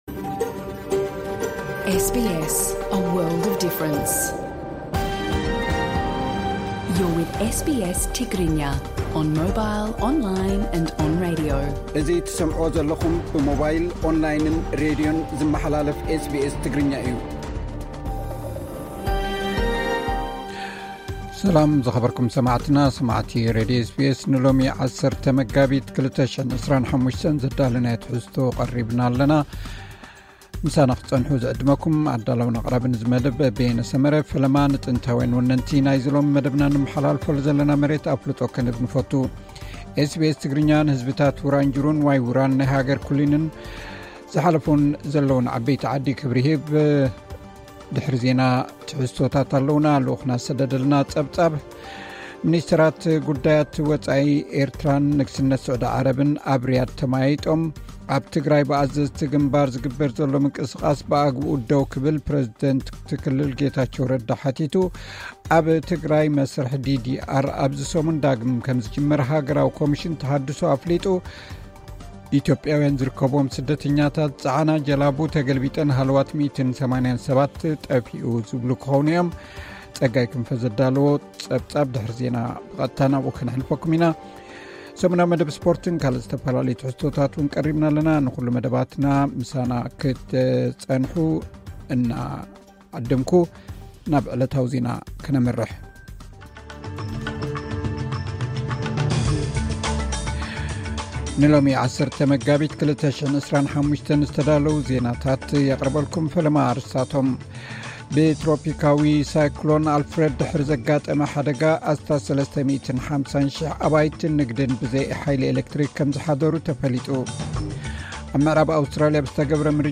ዕለታዊ ዜና ኤስ ቢ ኤስ ትግርኛ (10 መጋቢት 2025)